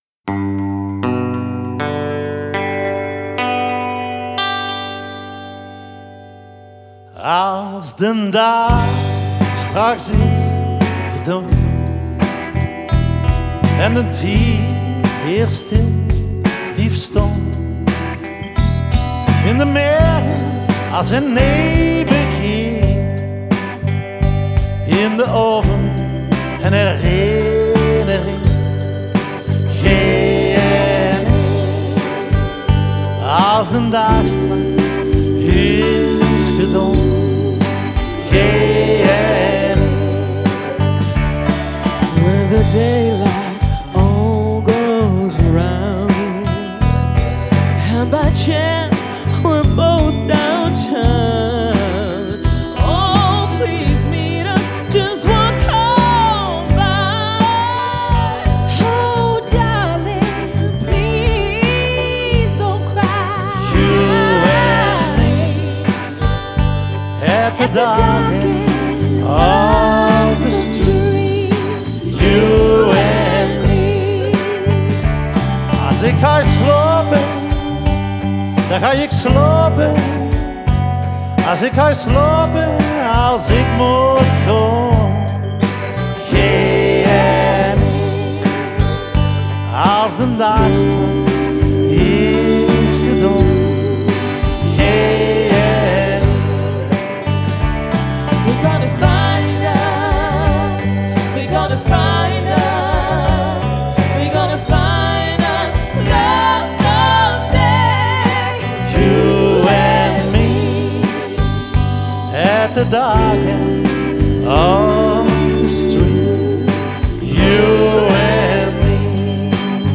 Они поют на северном нижне-франкском диалекте.
Genre: Ballad, folk